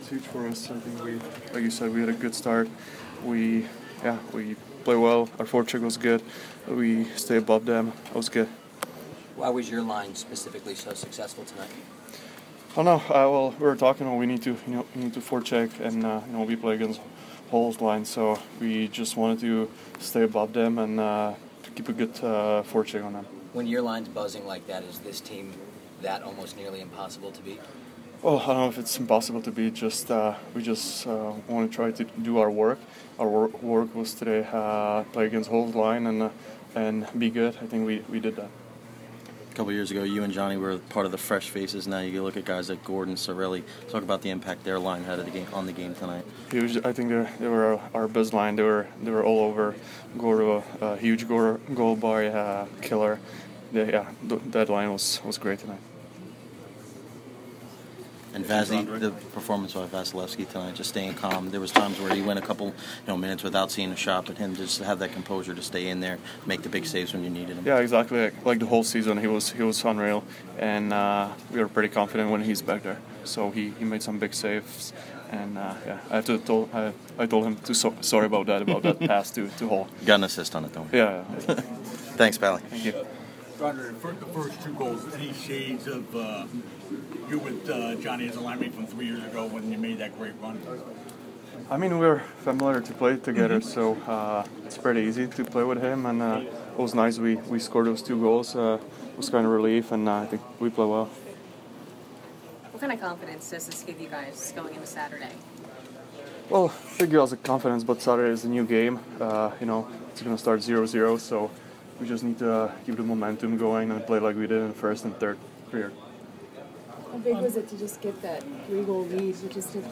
Ondrej Palat post-game 4/12